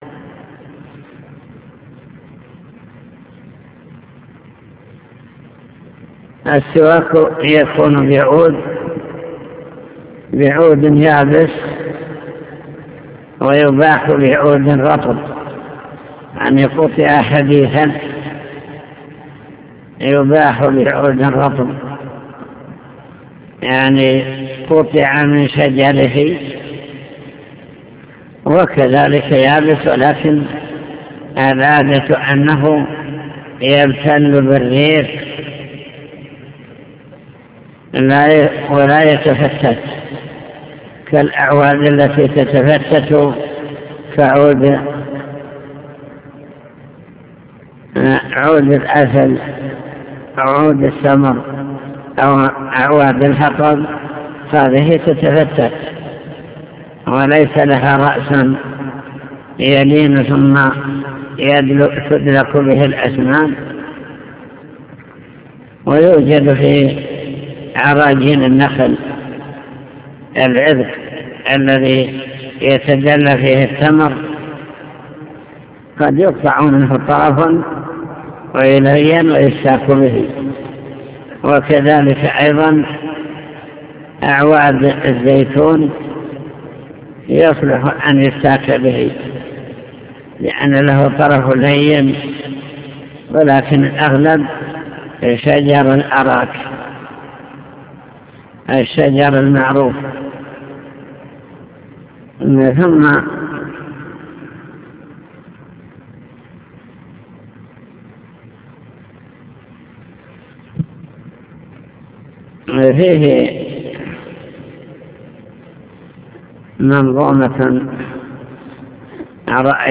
المكتبة الصوتية  تسجيلات - كتب  شرح كتاب دليل الطالب لنيل المطالب كتاب الطهارة باب في السواك